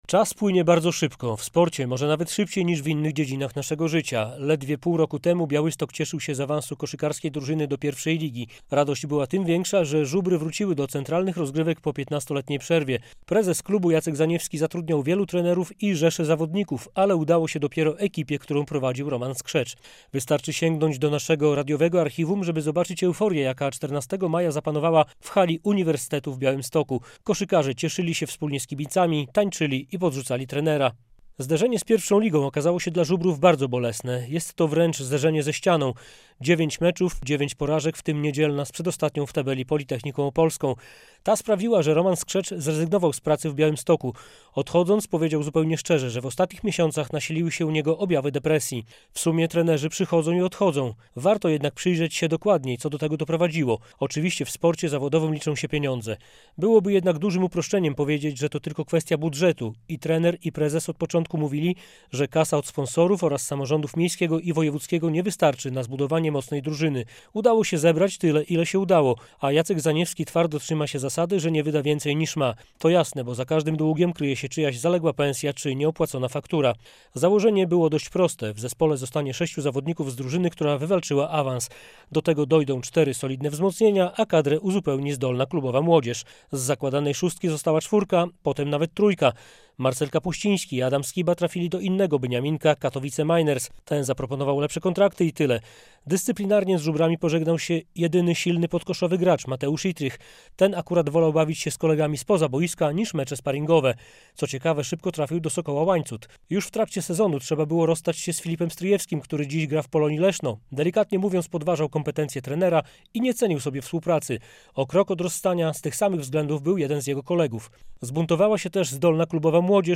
Radio Białystok | Felieton